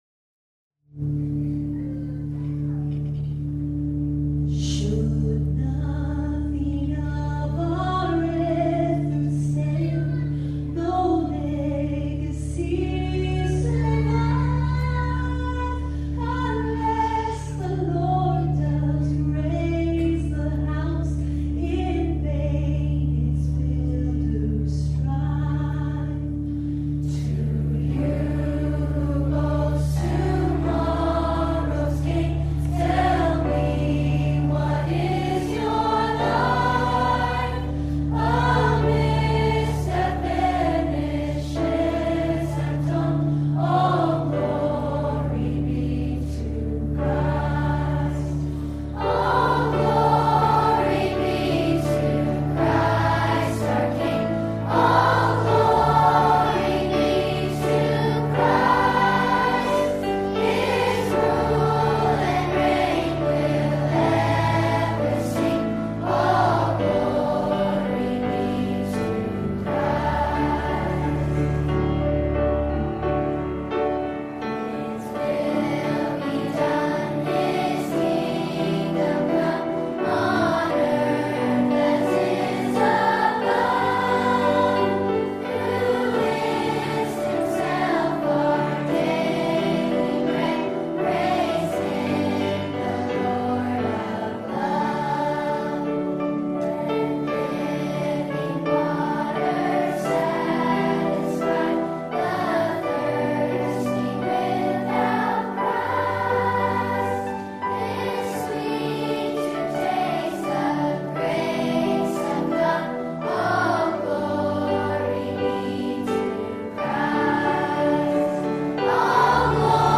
REcent Concerts